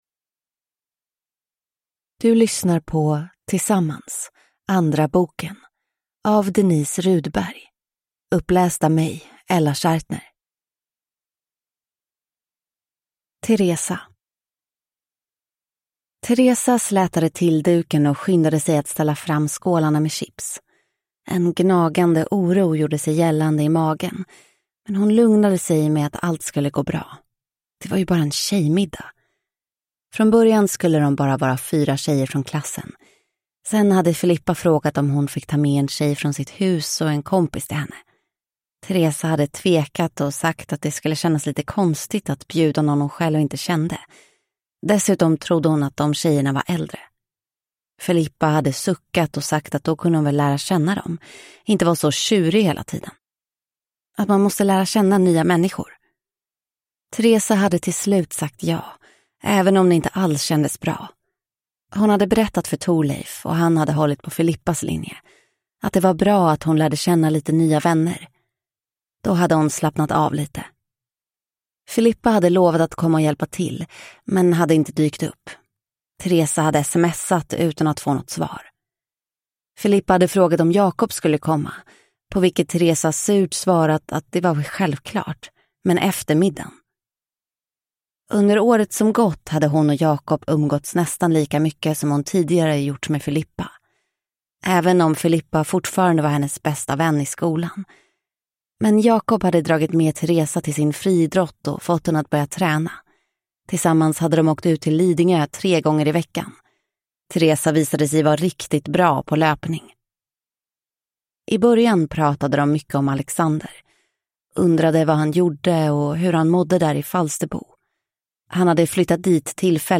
Tillsammans: andra boken (ljudbok) av Denise Rudberg